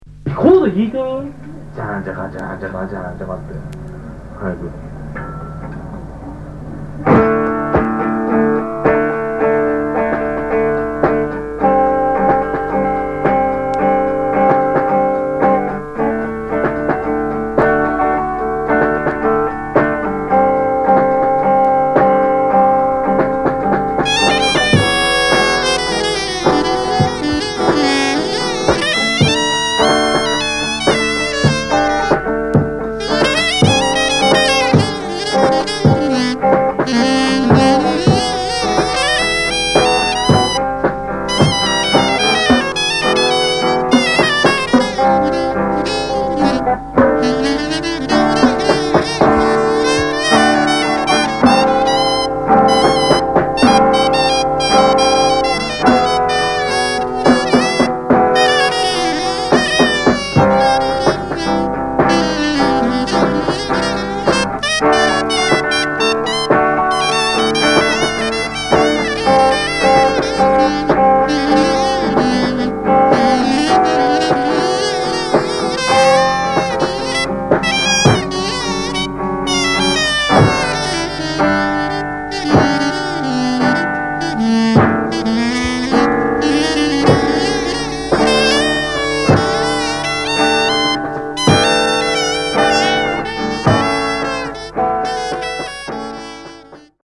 即興　脱線パンク　宅録